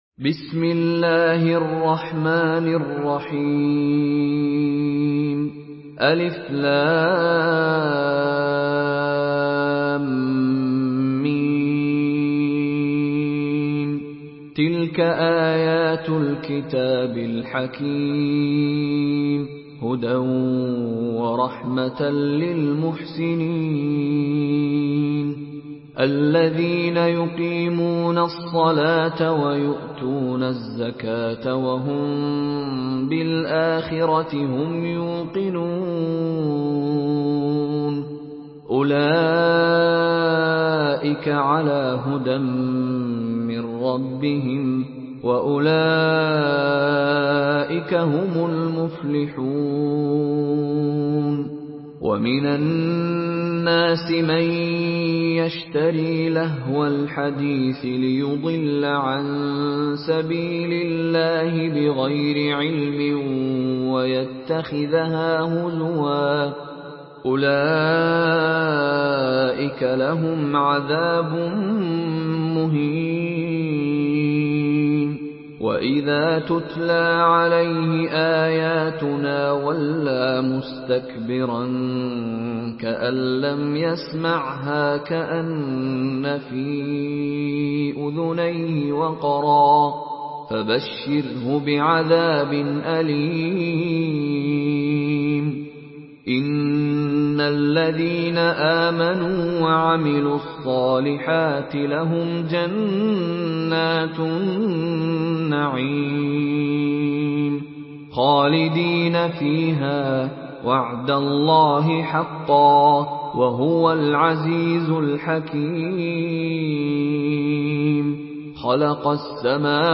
Surah Lokman MP3 in the Voice of Mishary Rashid Alafasy in Hafs Narration
Murattal